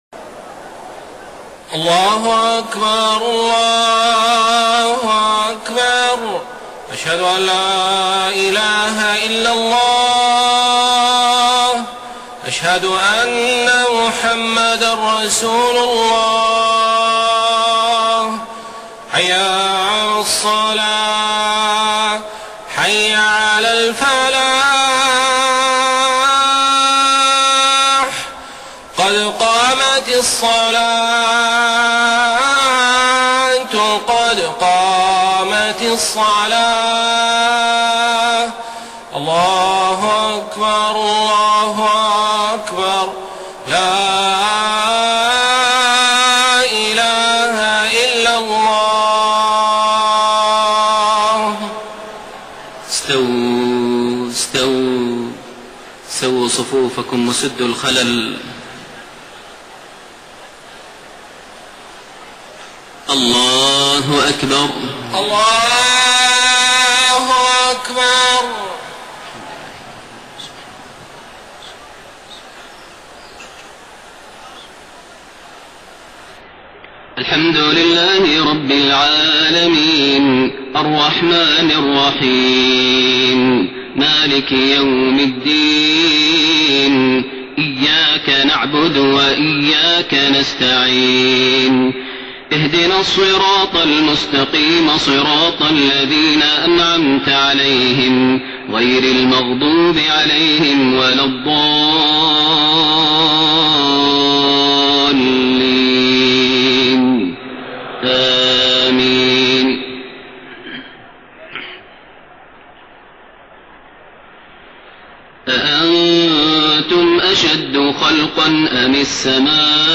صلاة العشاء 13 ذو الحجة 1432هـ خواتيم سورتي النازعات 27-46 و عبس 33-42 > 1432 هـ > الفروض - تلاوات ماهر المعيقلي